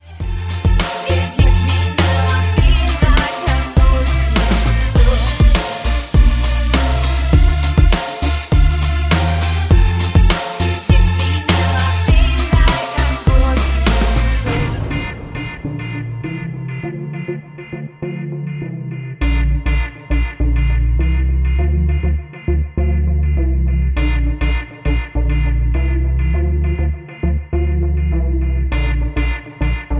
Dm
MPEG 1 Layer 3 (Stereo)
Backing track Karaoke
Pop, 2010s